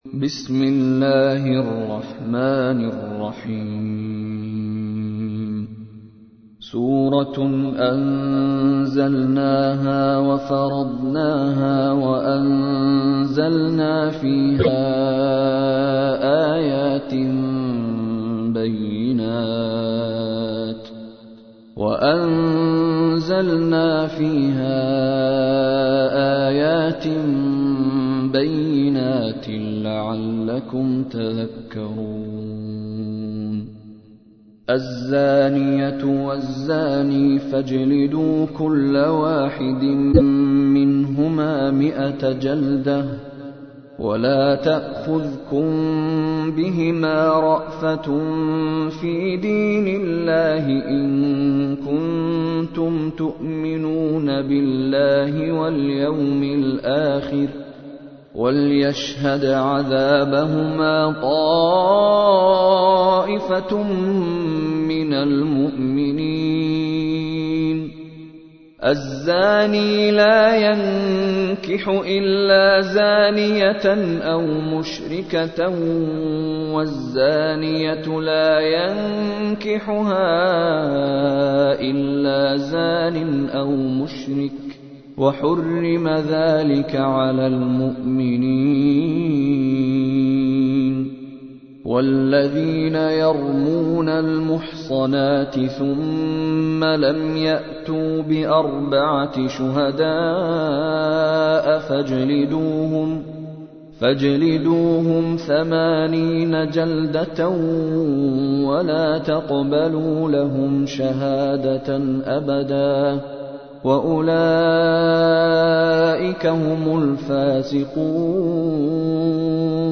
تحميل : 24. سورة النور / القارئ مشاري راشد العفاسي / القرآن الكريم / موقع يا حسين